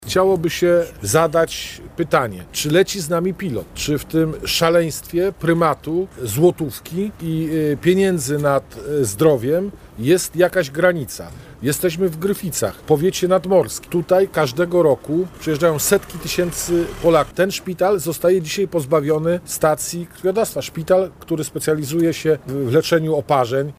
Decyzją Regionalnego Centrum Krwiodawstwa i Krwiolecznictwa w Szczecinie, zamknięty został punkt w Świnoujściu … ten sam los spotkał oddział w Gryficach – mówi Europoseł PiS, Joachim Brudziński: